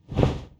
Close Combat Swing Sound 21.wav